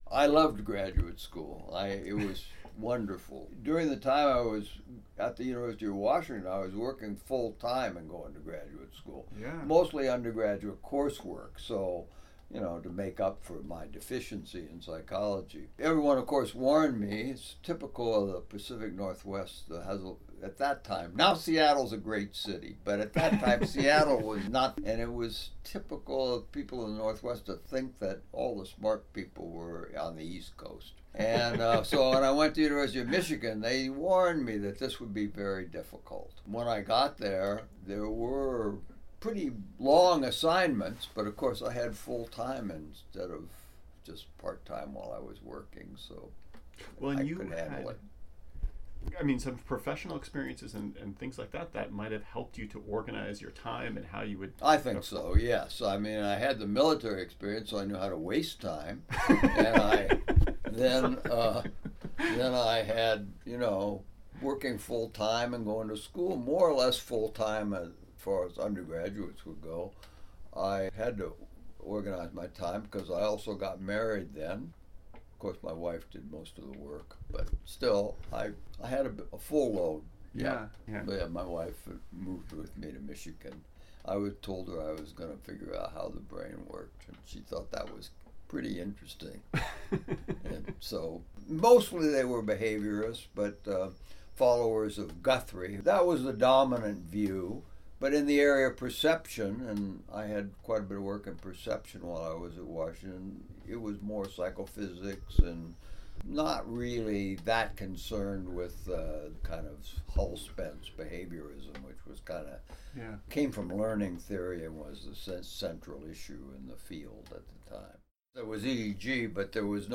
In this next excerpt Dr. Posner describes going to grad school and what was required of him to complete his dissertation: